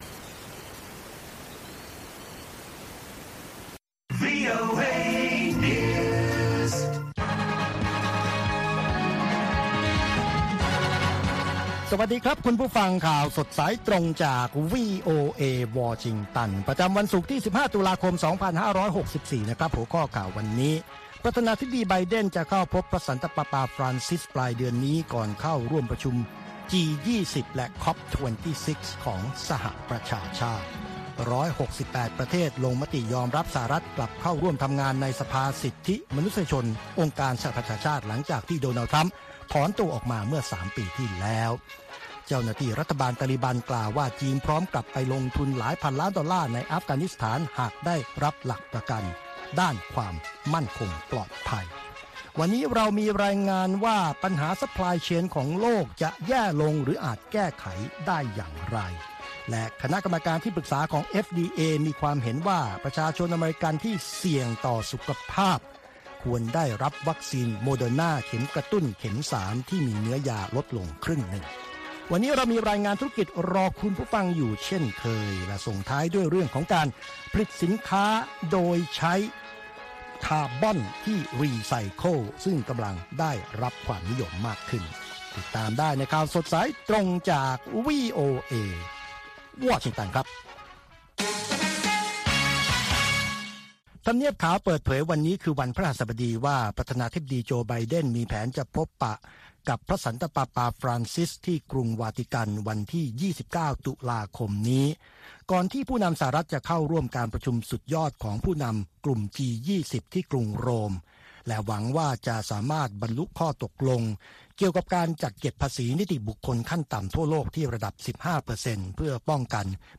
ข่าวสดสายตรงจากวีโอเอ ภาคภาษาไทย 6:30 – 7:00 น. ประจำวันศุกร์ที่ 15 ตุลาคมตามเวลาในประเทศไทย